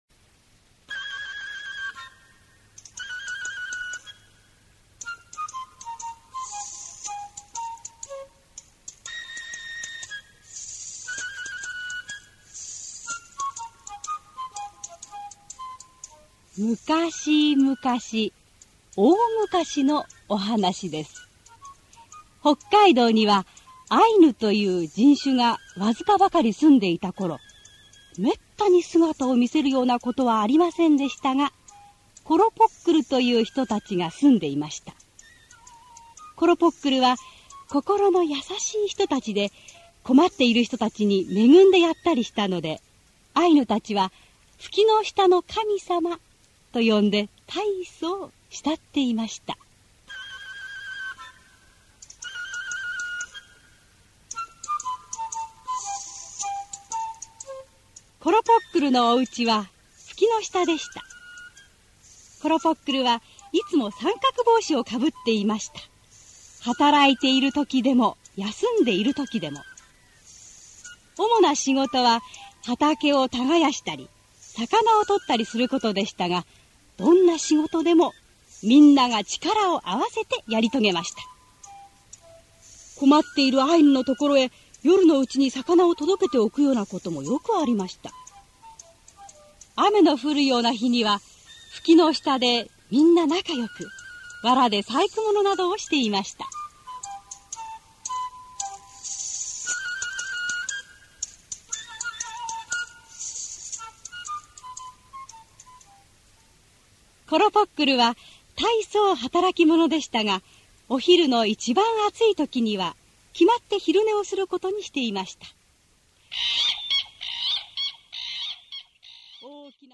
[オーディオブック] ころぼっくる